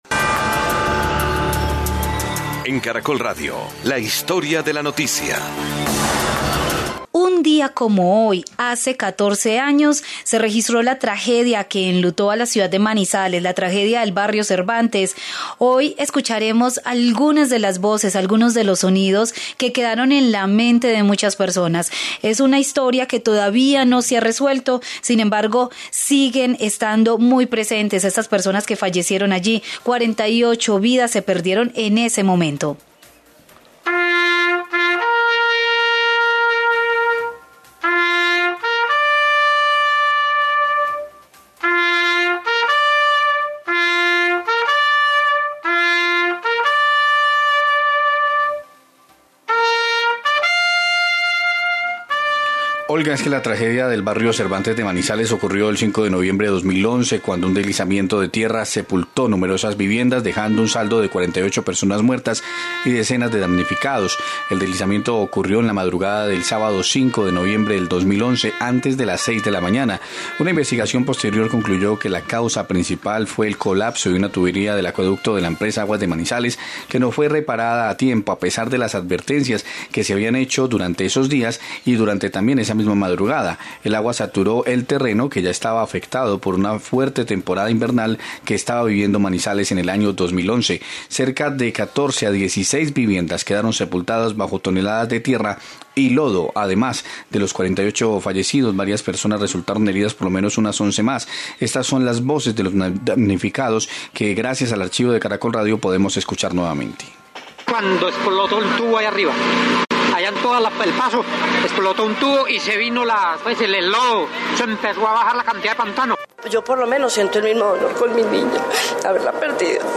Hoy se cumplen 14 años de la tragedia del barrio Cervantes, escucharemos las voces de los damnificados de los socorristas y de los abogados que aún están reclamando justicia para los damnificados del barrio. El 2011 fue considerado uno de los años más difíciles para la historia moderna de la ciudad de Manizales
INFORME ESPECIAL TRAGEDIA CERVANTES